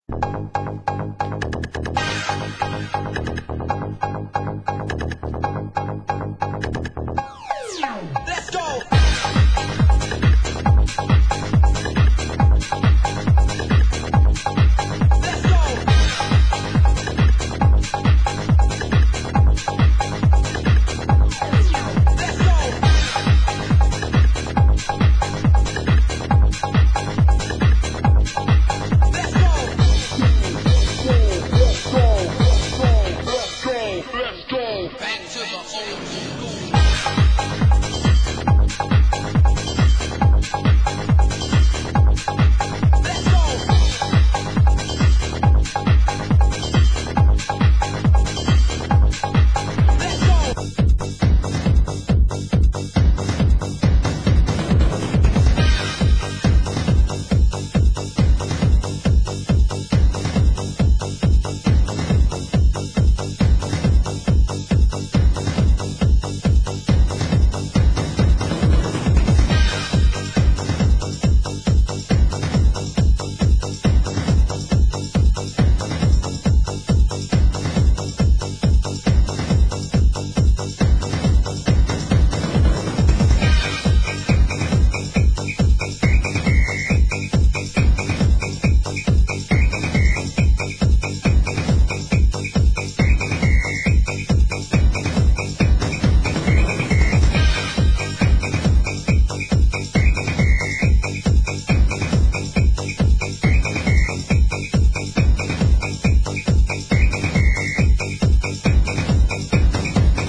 Genre Hard House